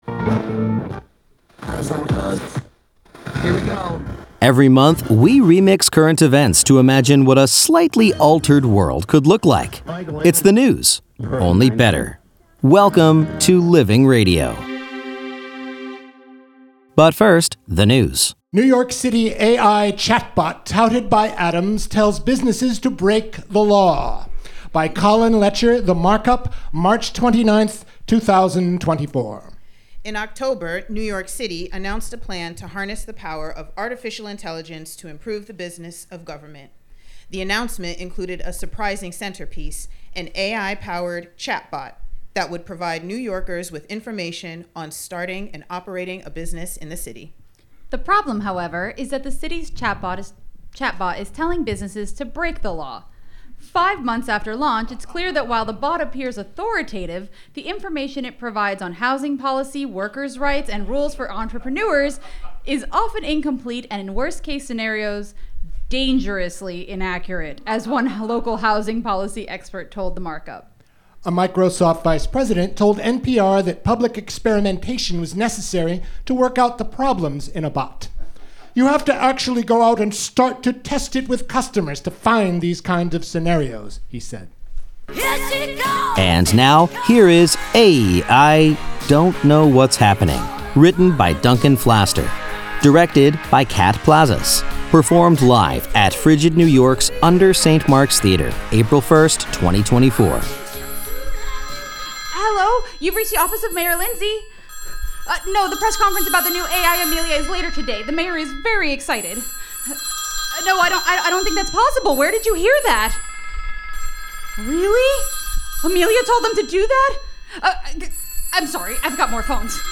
performed live for Living Radio at FRIGID New York’s UNDER St. Mark’s Theater, April 1, 2024